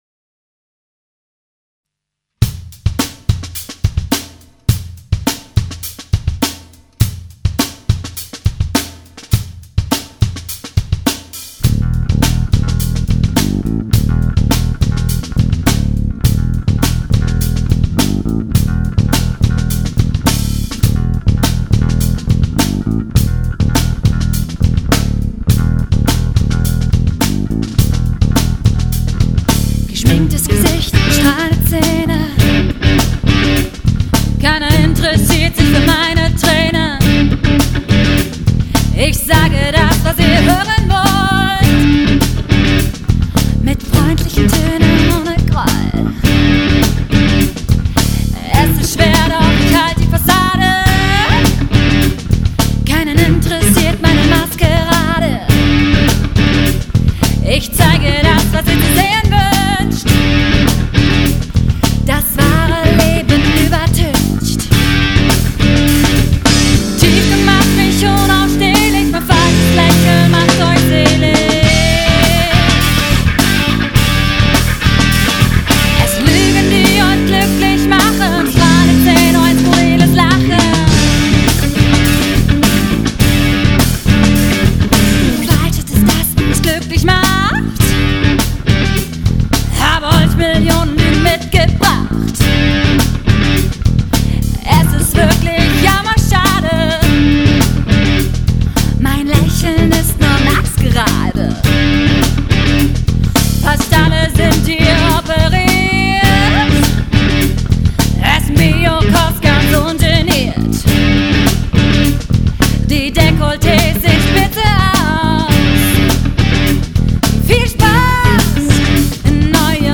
Demo Songs